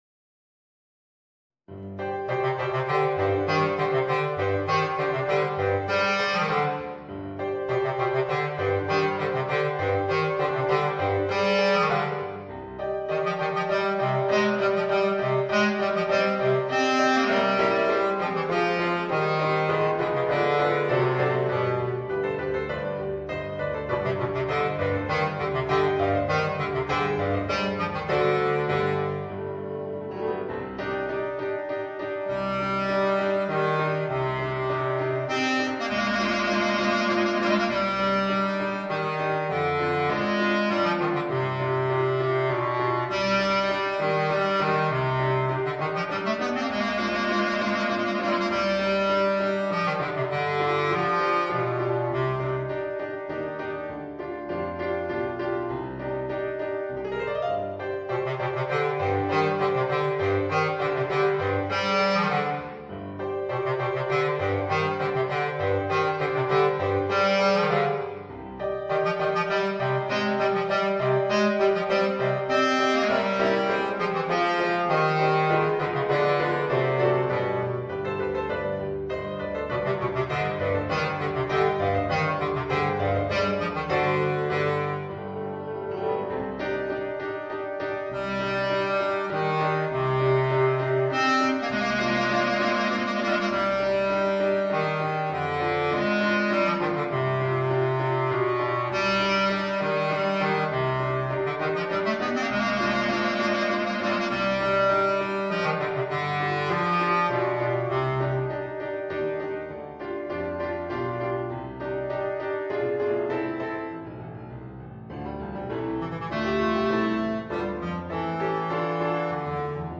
Voicing: Bass Clarinet and Piano